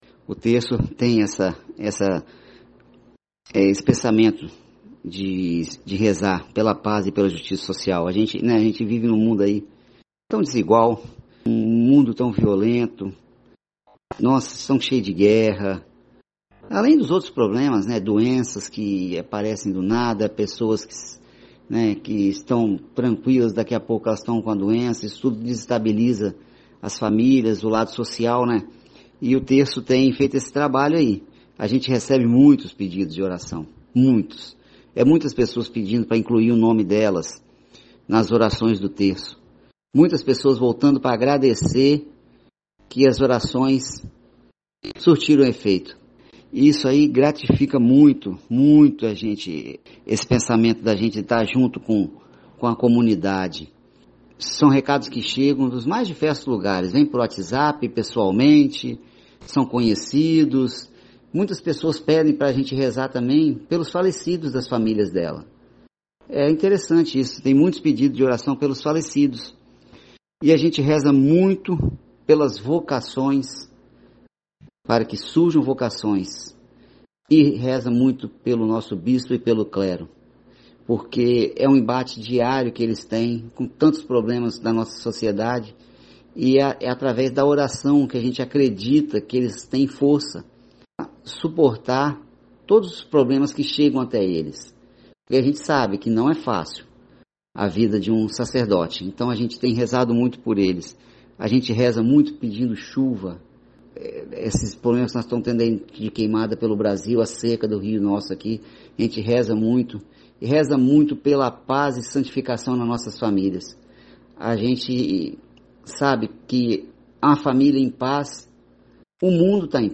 Pela primeira vez, adotamos o formato de entrevista com respostas em áudio (mp3).